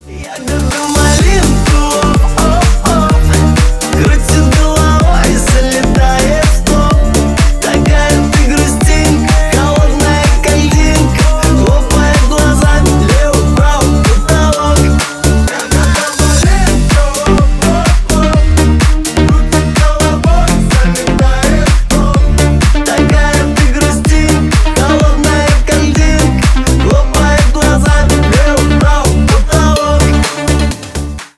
• Качество: 320 kbps, Stereo
Ремикс
клубные
громкие